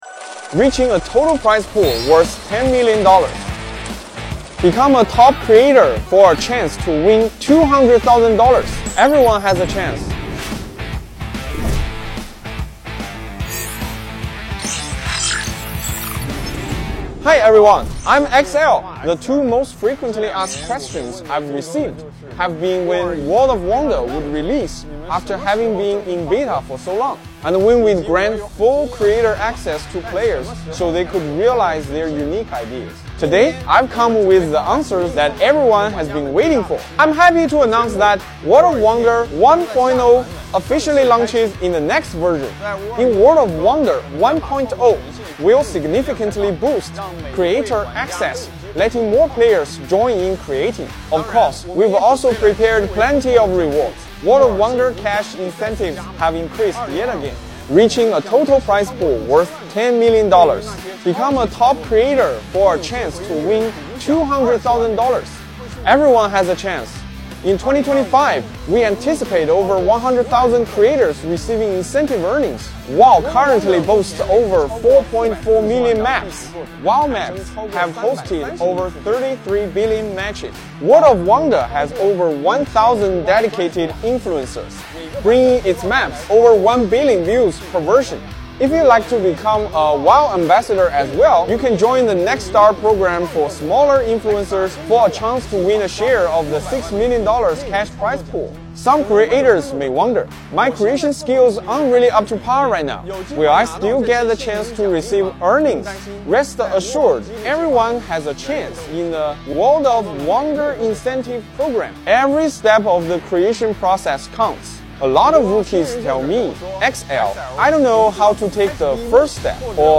🎙 We’re celebrating with a Dev Talk discussing what’s new, including a massive rewards pool worth $10 million!